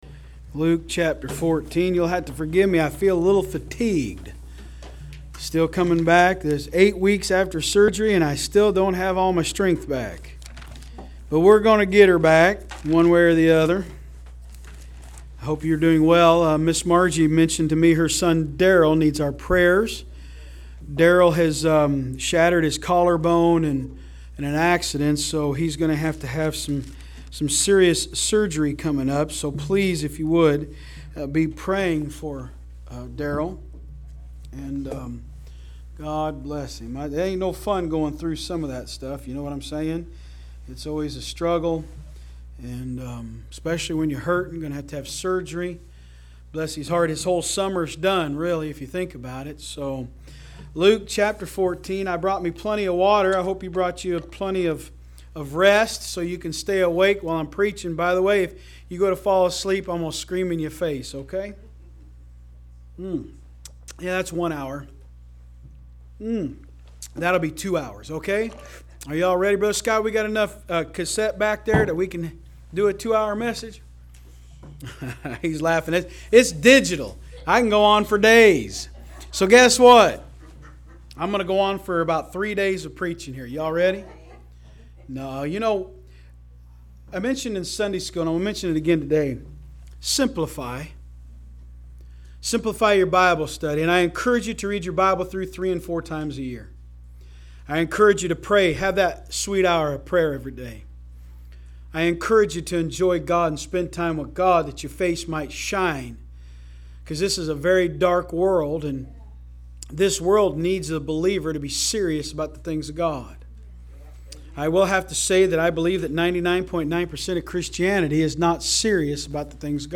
From Series: "AM Service"